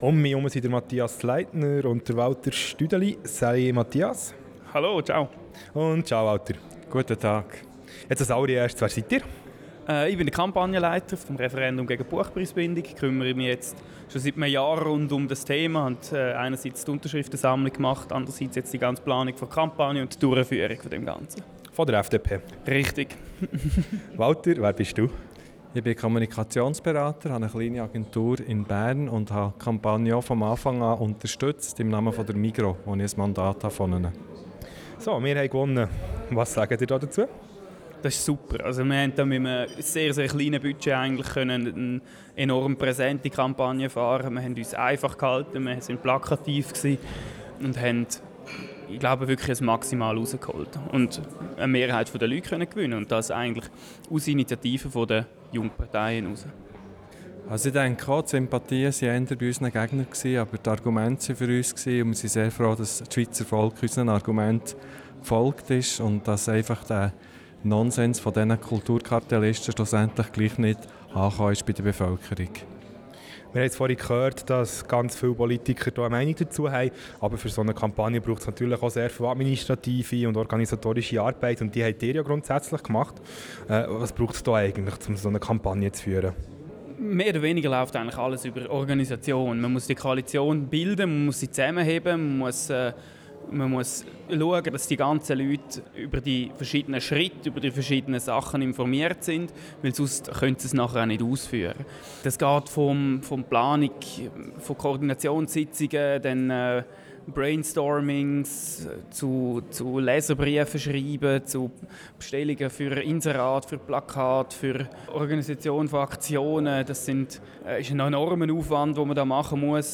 Abstimmungsparty